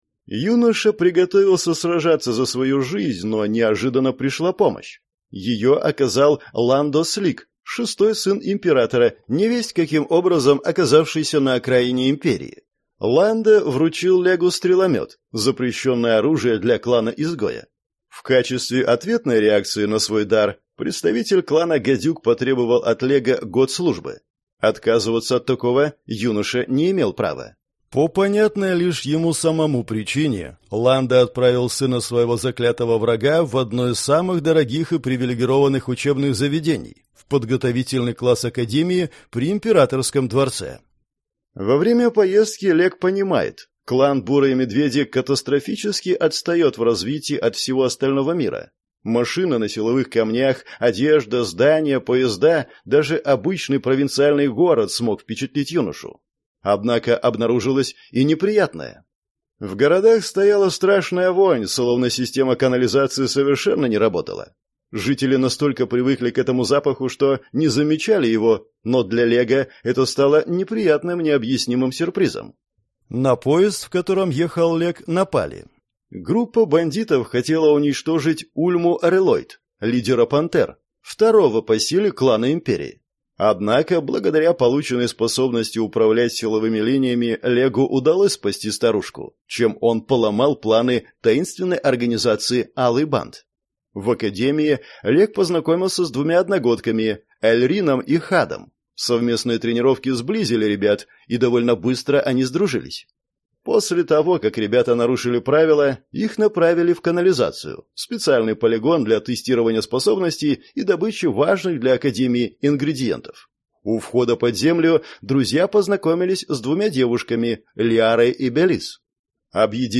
Аудиокнига Клан Медведя. Книга 4. Медвемаг | Библиотека аудиокниг